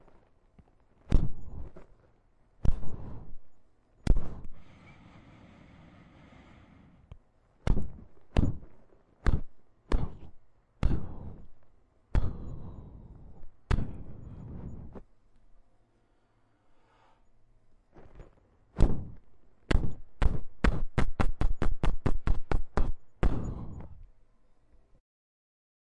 爆炸
描述：我实际上是在边走边敲打广告牌但是当我正在努力时，它听起来更像爆炸。但我不得不删除背景噪音有流量我无法删除所有背景噪音但它仍然听起来像爆炸。
标签： 热潮 OWI 爆炸 爆炸
声道立体声